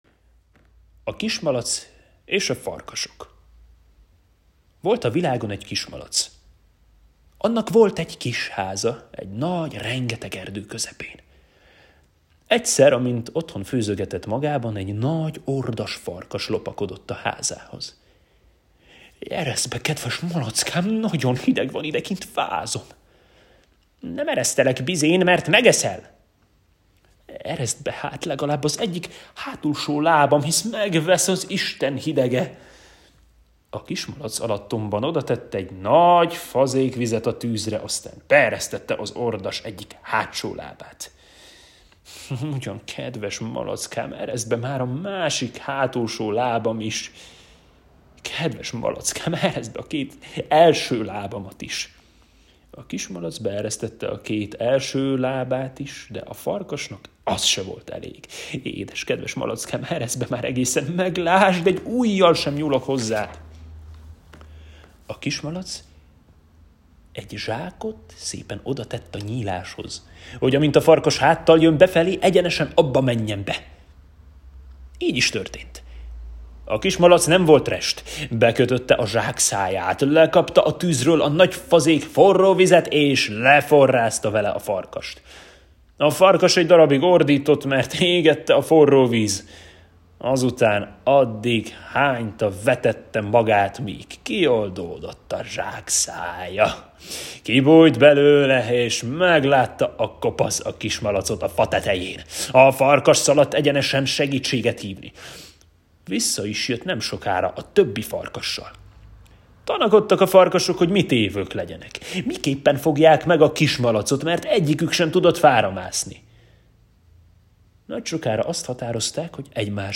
Hangos mesék